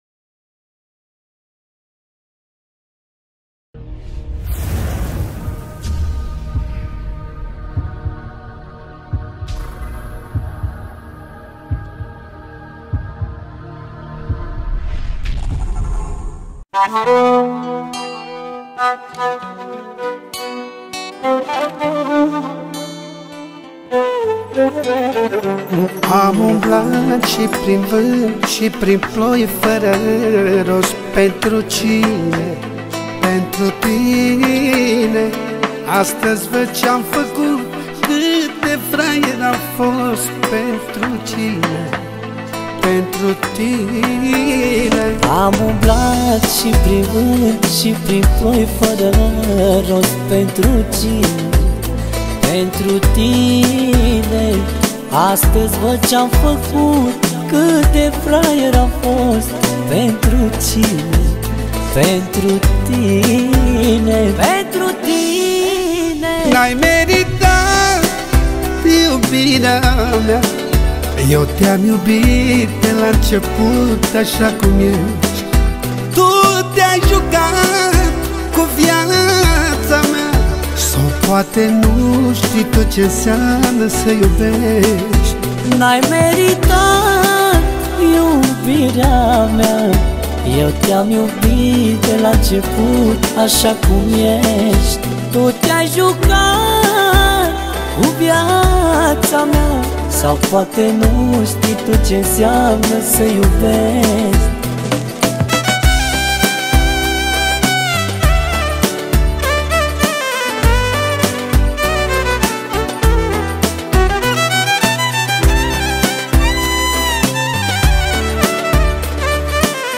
o piesă care îmbină emoție și ritm
Manele Vechi